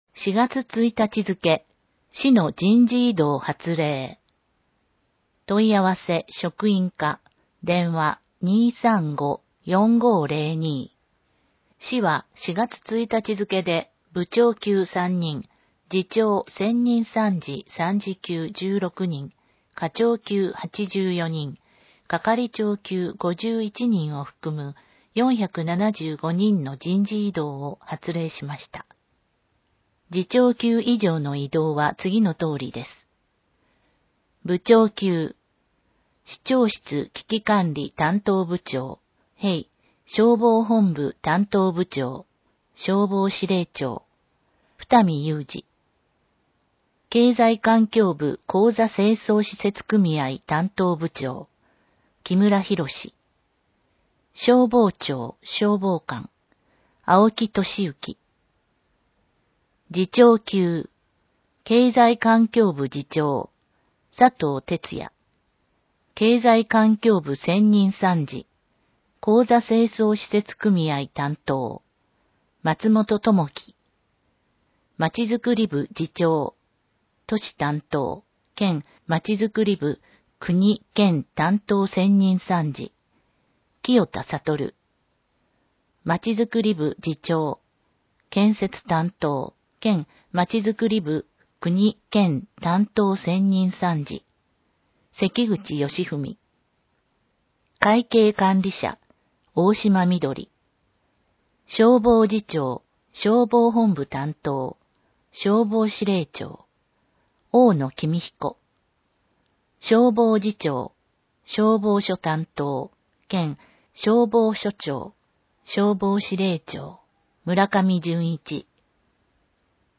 広報えびな 令和3年4月15日号（電子ブック） （外部リンク） PDF・音声版 ※音声版は、音声訳ボランティア「矢ぐるまの会」の協力により、同会が視覚障がい者の方のために作成したものを登載しています。